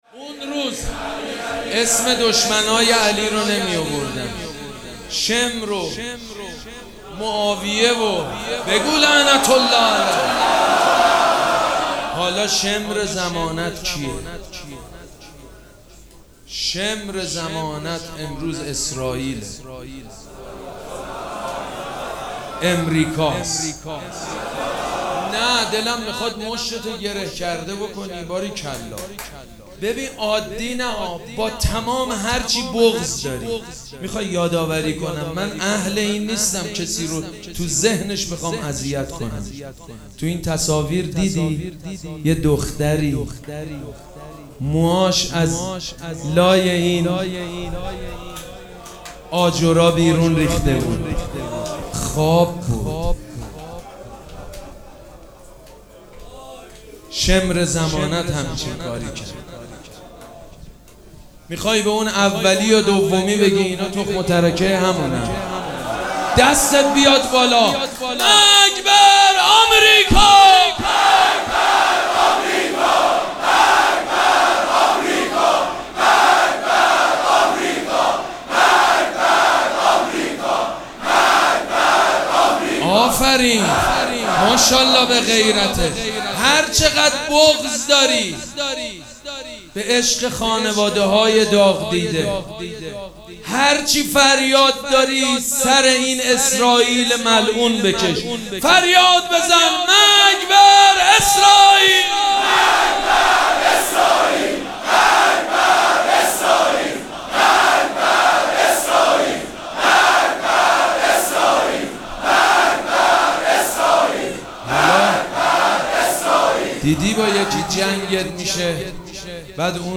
مراسم عزاداری شب دوم محرم الحرام ۱۴۴۷
حسینیه ریحانه الحسین سلام الله علیها
مداح
حاج سید مجید بنی فاطمه